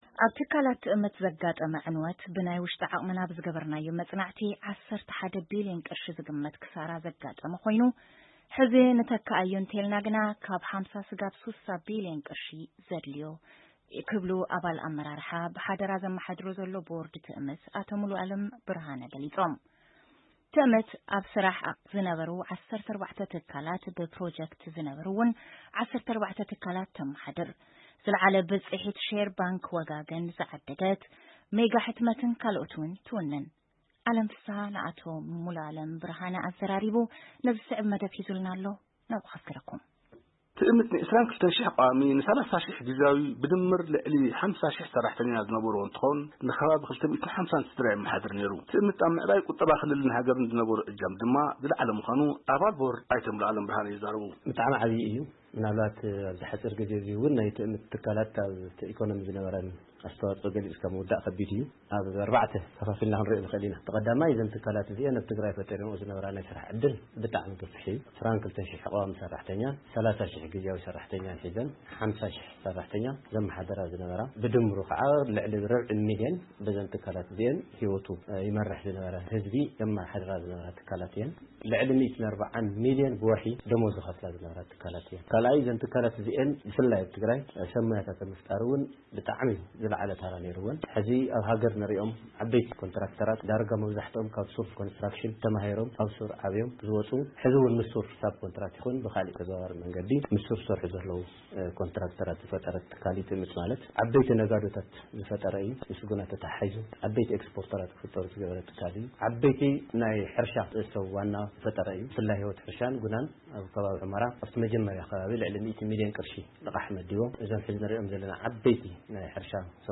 ቃለ መጠይቕ